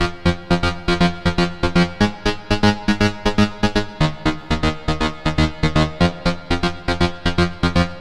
loops basses dance 120 - 2
Basse dance 5 E